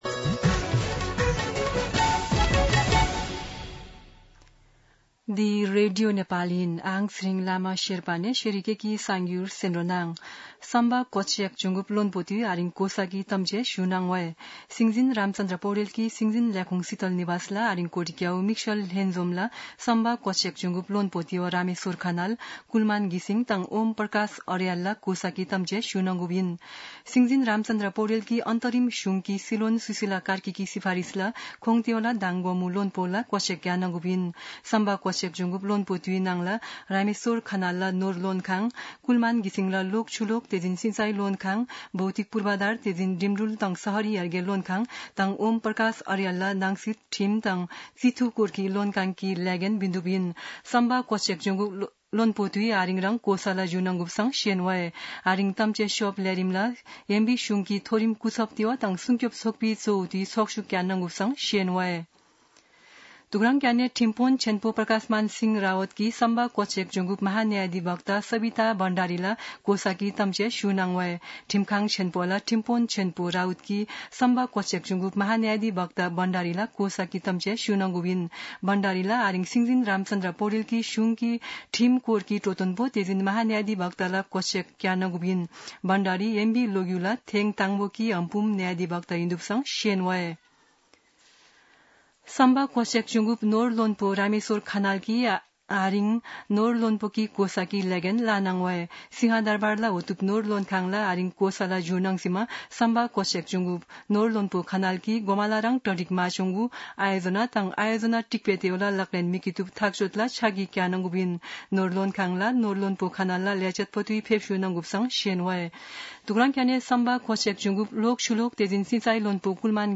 शेर्पा भाषाको समाचार : ३० भदौ , २०८२
Sherpa-News-30.mp3